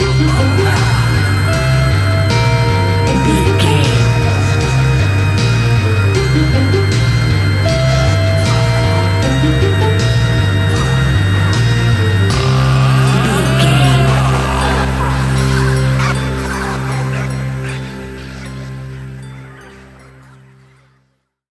Aeolian/Minor
ominous
haunting
eerie
strings
synthesiser
percussion
electric guitar
drums
electric organ
harp
horror music
horror instrumentals